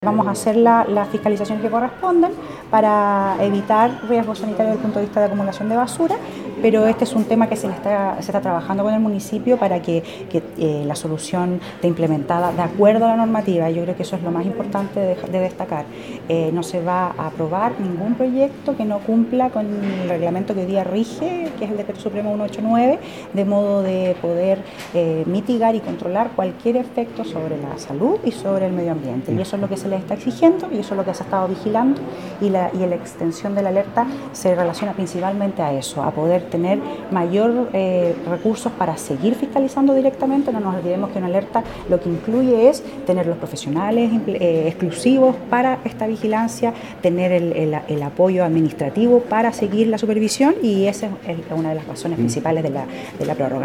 Ante esto la Seremi de Salud en Los Lagos, Scarlett Molt, indicó que la iniciativa se está realizando bajo toda la legalidad correspondiente, la que además se enmarca en la prórroga del estado de Alerta Sanitaria que se extenderá en Chiloé por otros seis meses.